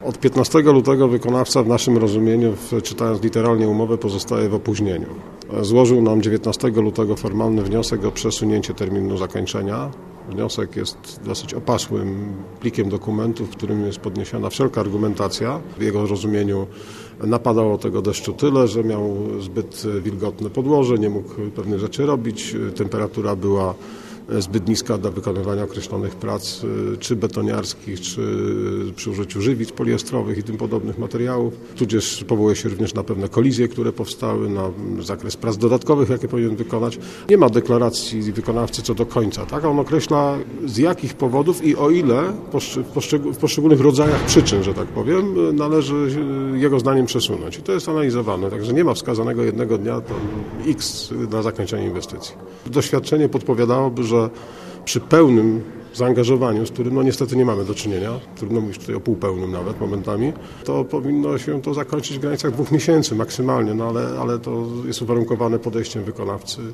Naszym gościem był Miejski Inżynier Ruchu, Łukasz Dondajewski, który zatwierdza każdy remont w Poznaniu i odpowiada za organizację ruchu w mieście.